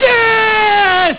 Grandpa yells, "DEATH!"